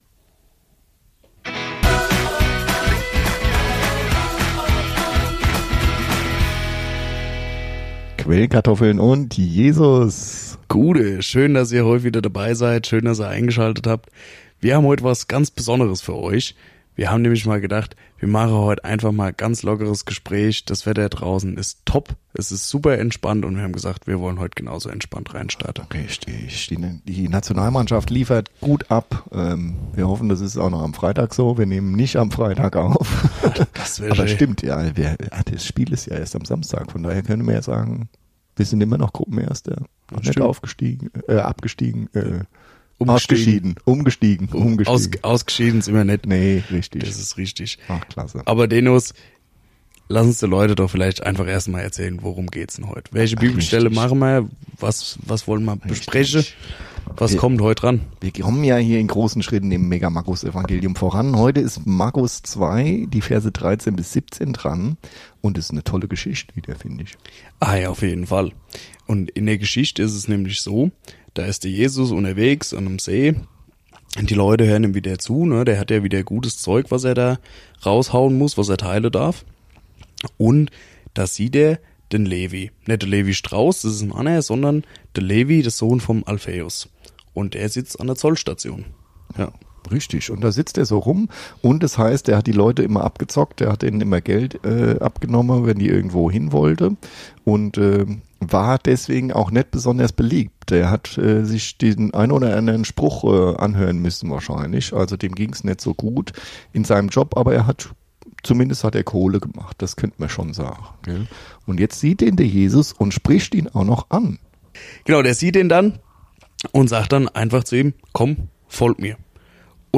ungeschnidde un original.